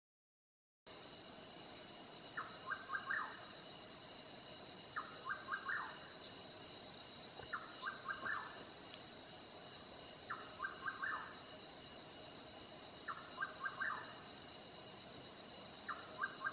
Rufous Nightjar (Antrostomus rufus)
Life Stage: Adult
Location or protected area: Parque Nacional Chaco
Condition: Wild
Certainty: Recorded vocal